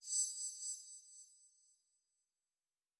Magic Chimes 06.wav